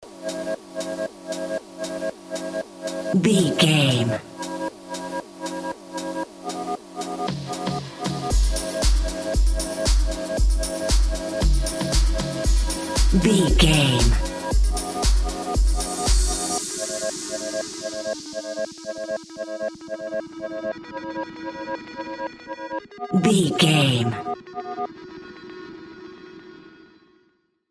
Aeolian/Minor
ethereal
dreamy
cheerful/happy
groovy
synthesiser
drum machine
house
electro dance
synth leads
synth bass
upbeat